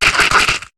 Cri d'Écrapince dans Pokémon HOME.